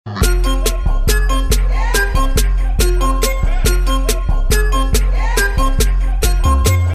• Качество: 128, Stereo
громкие
Хип-хоп
электронная музыка